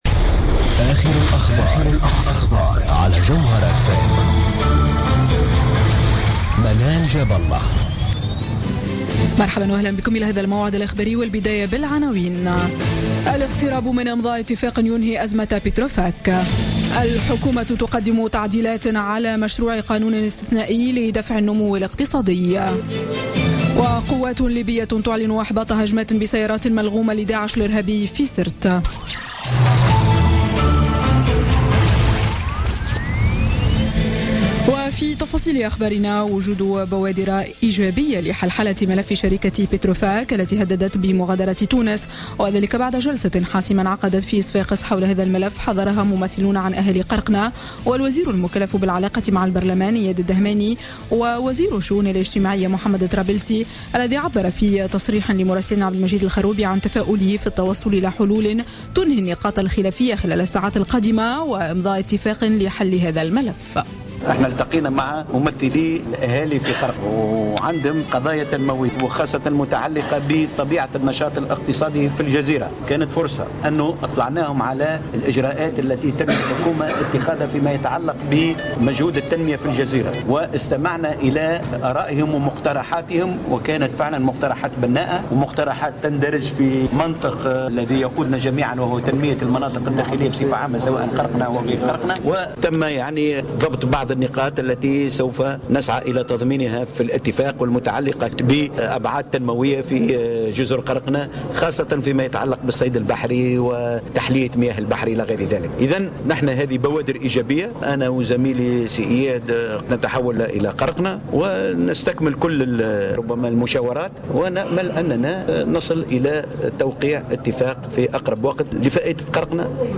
Journal Info 00h00 du vendredi 23 septembre 2016